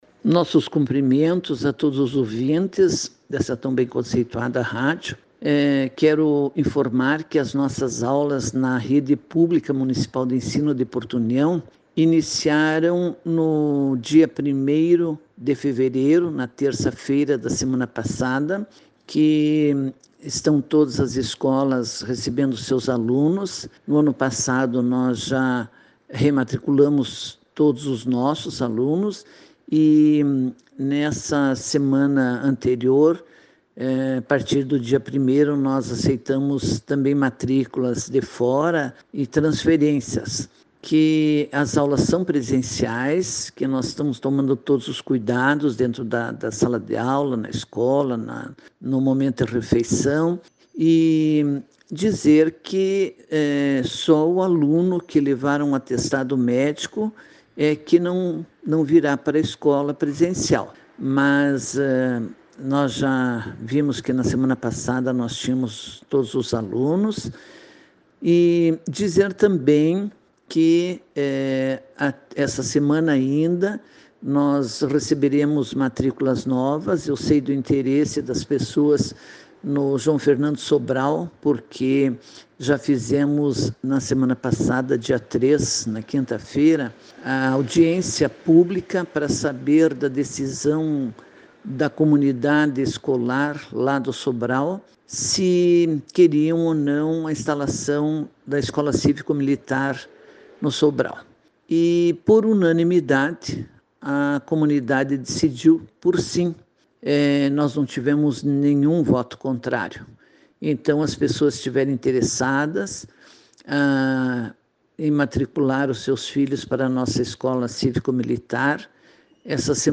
Acompanhe o áudio da secretária abaixo: